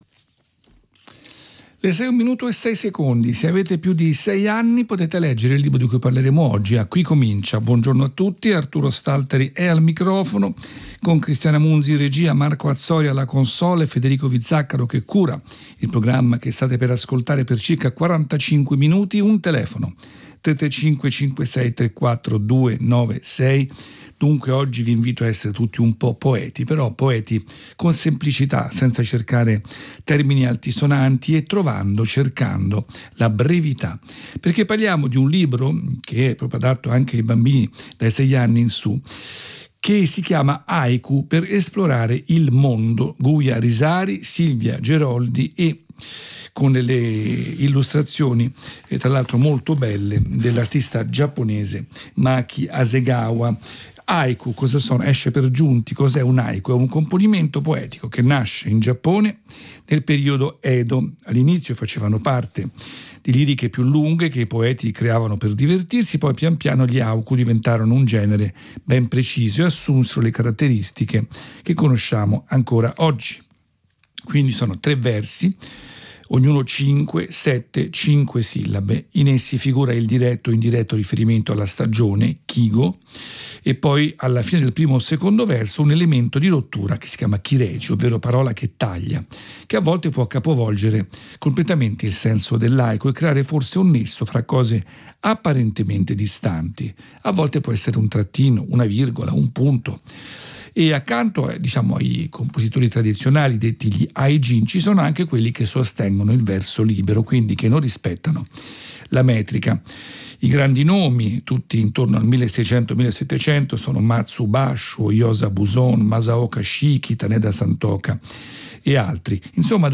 Radio comment